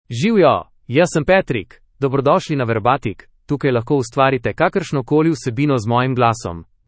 Patrick — Male Slovenian AI voice
Patrick is a male AI voice for Slovenian (Slovenia).
Voice sample
Male